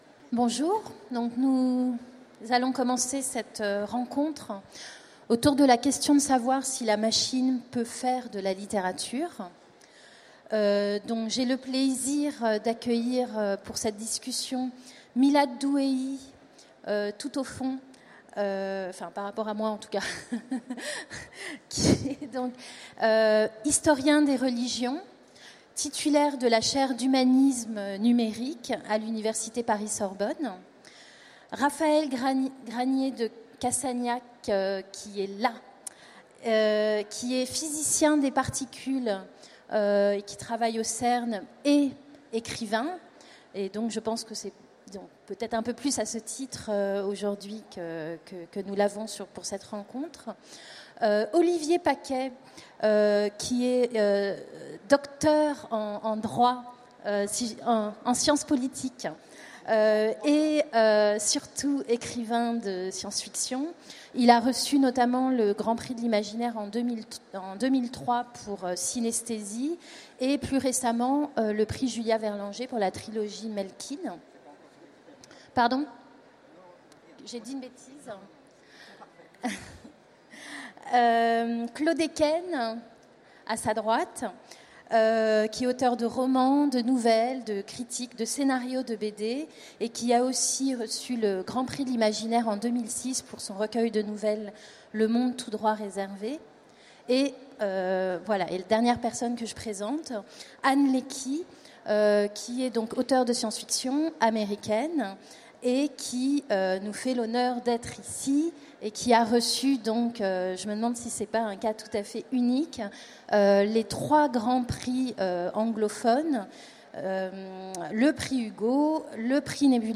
Utopiales 2016 : Conférence La machine peut-elle faire de la littérature ?